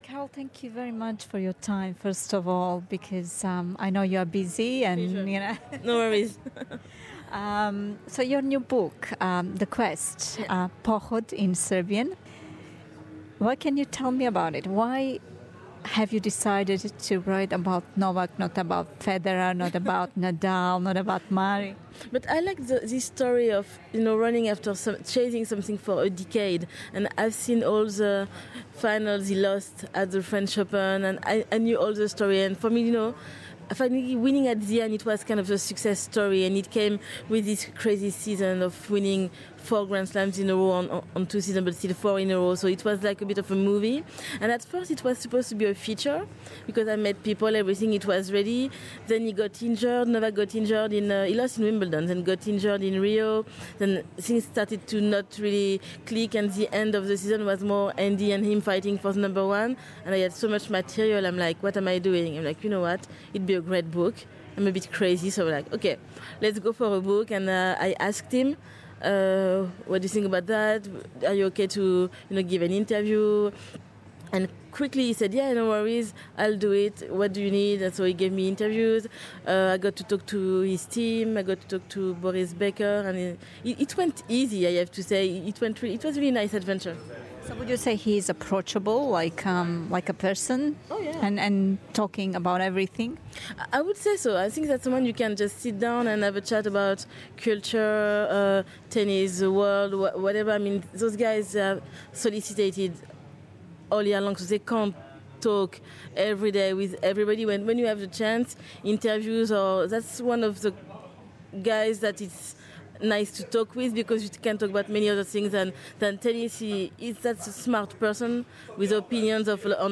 Слушајте верзију интервјуа на српском.